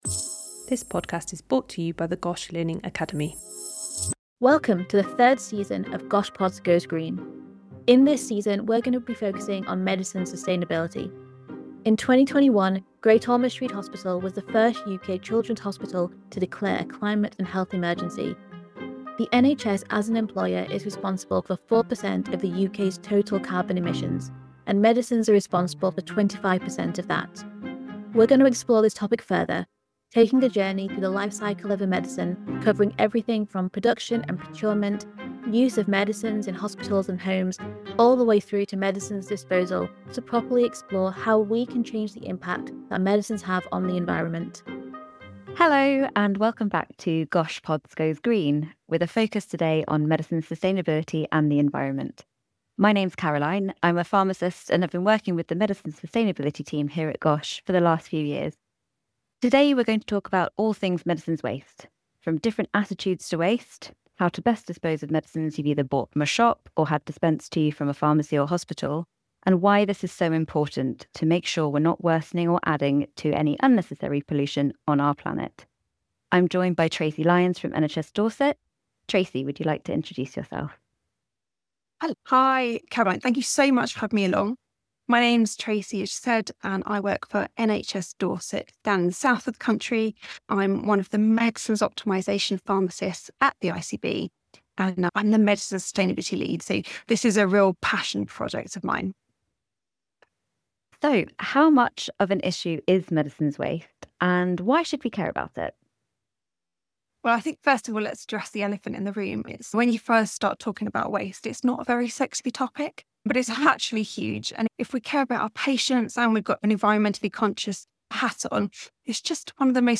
This conversation is packed with practical ideas and inspiring reflections on how returning to first principles could help us reduce waste before it even begins.